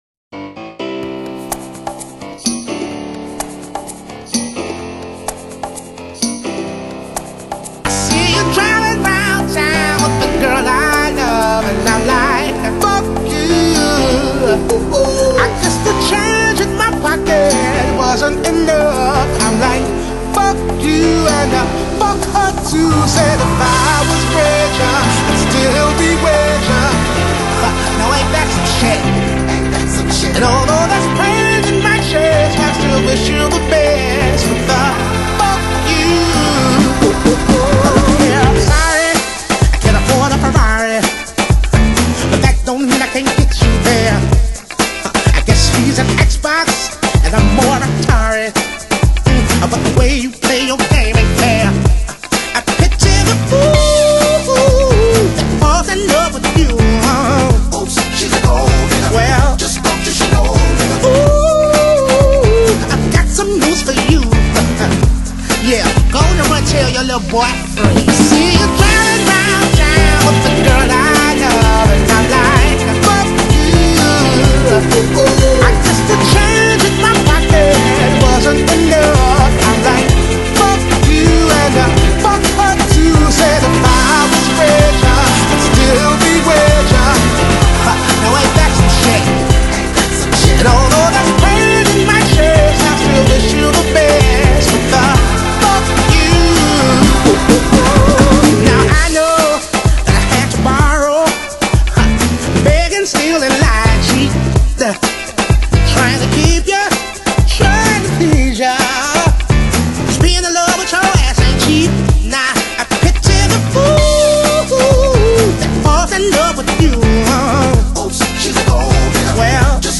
Genre: R&B, Neo-Soul